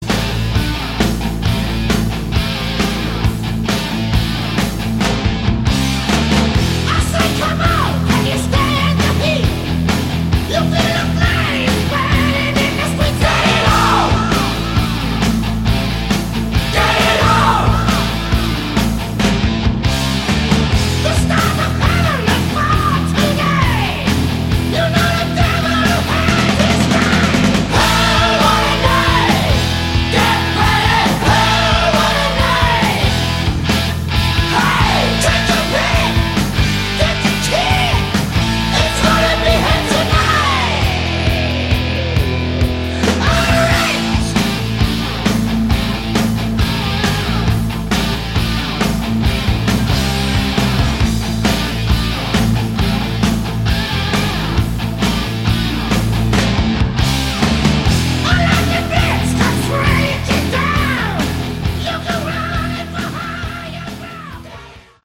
Category: Metal